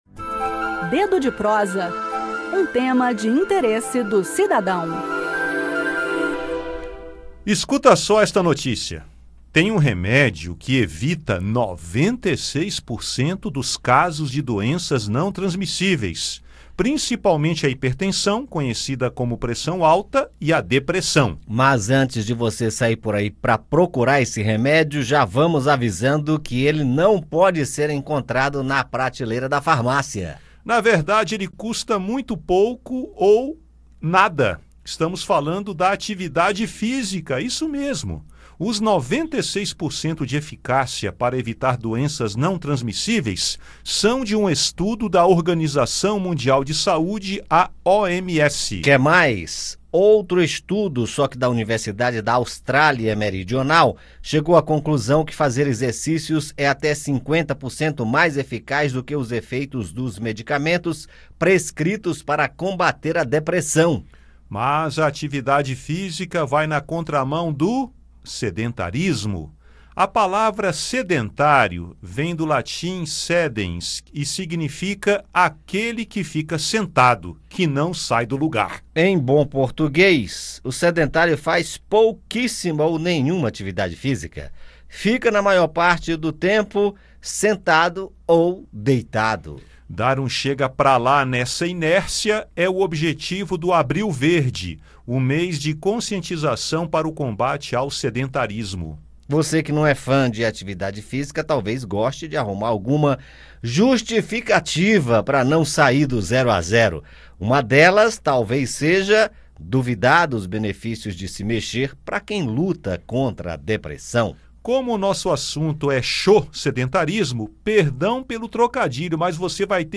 A campanha Abril Verde de conscientização para o combate ao sedentarismo visa alertar o cidadão para a necessidade de atividades físicas na prevenção de doenças não transmissíveis. Há comprovações científicas de que uma boa caminhada, corrida ou pedalada, além de propiciar bem-estar físico e mental, ajudam a previnir doenças como a hipertensão e a depressão. Ouça no bate-papo.